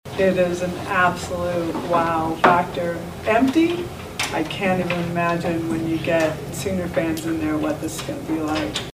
CLICK HERE to listen to commentary from OU Coach Patty Gasso.